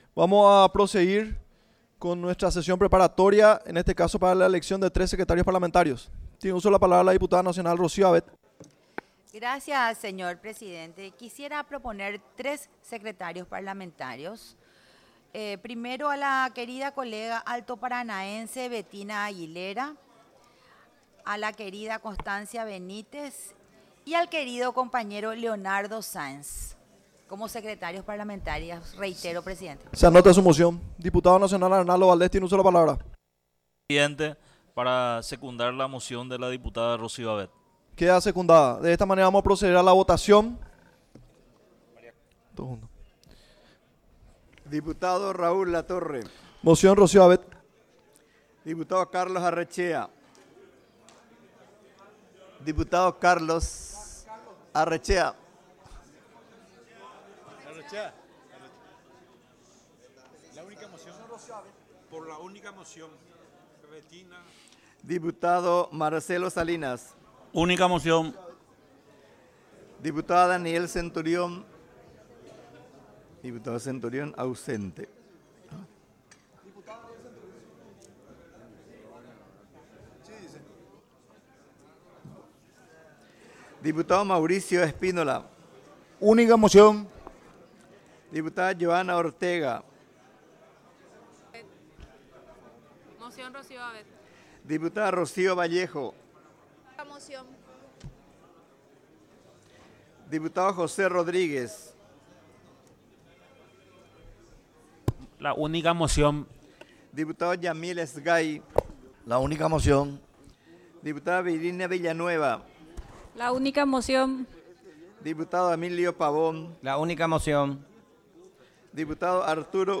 Sesi�n Preparatoria, 4 de marzo de 2025